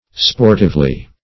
sportively - definition of sportively - synonyms, pronunciation, spelling from Free Dictionary
[1913 Webster] -- Sport"ive*ly, adv.